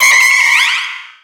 Cri de Méga-Nanméouïe dans Pokémon Rubis Oméga et Saphir Alpha.
Cri_0531_Méga_ROSA.ogg